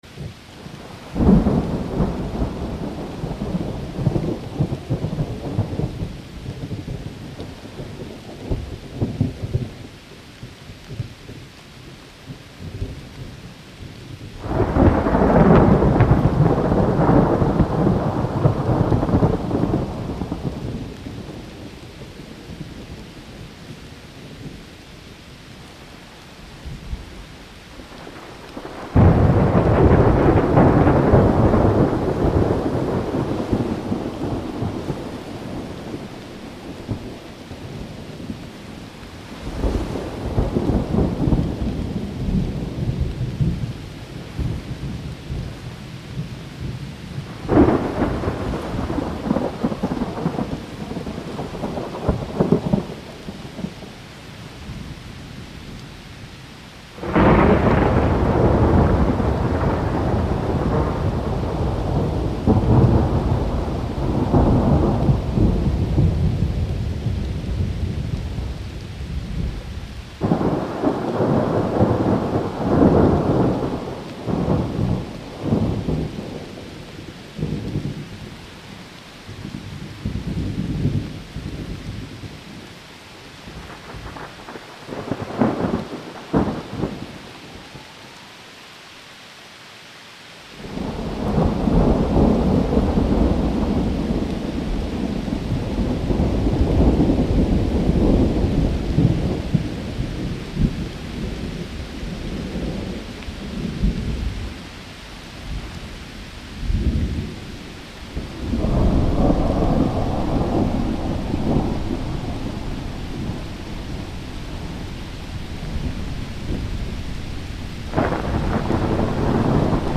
دانلود آهنگ رعد و برق و باران بسیار آرامش بخش و زیبا از افکت صوتی طبیعت و محیط
دانلود صدای رعد و برق و باران بسیار آرامش بخش و زیبا از ساعد نیوز با لینک مستقیم و کیفیت بالا
جلوه های صوتی